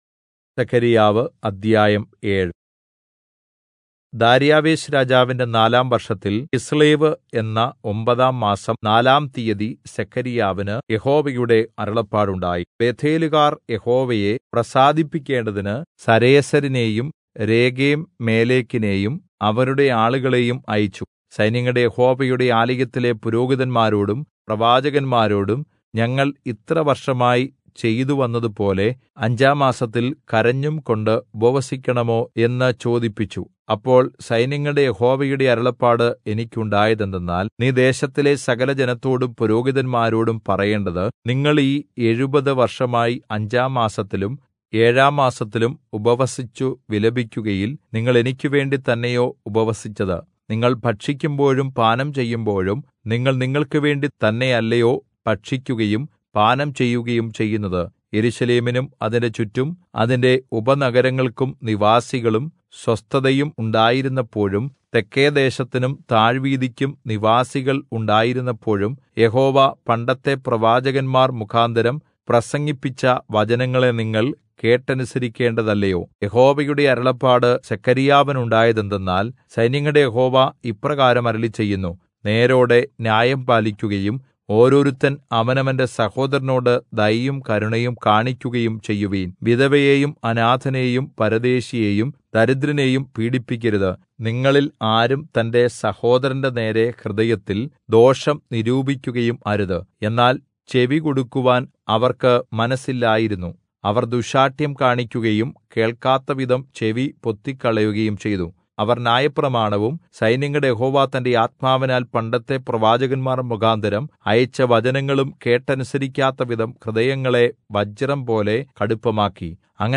Malayalam Audio Bible - Zechariah 12 in Irvml bible version